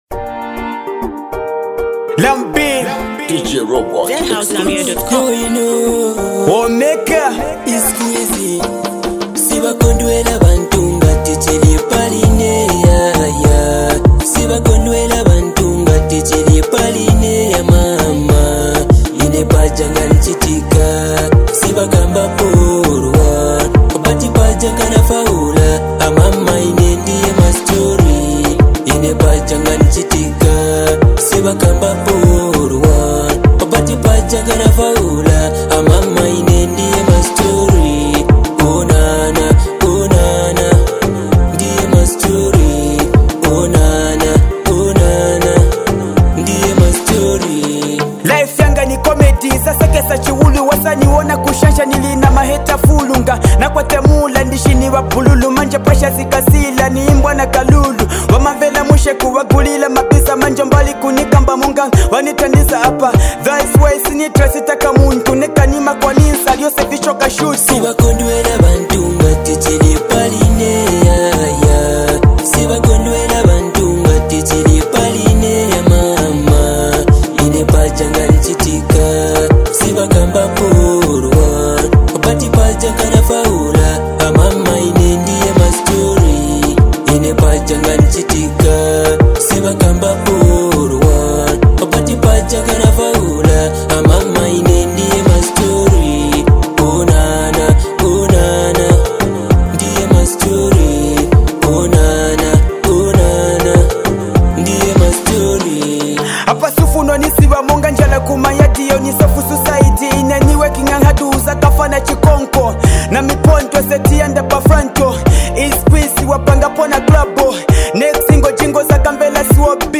With its uplifting beats and heartfelt lyrics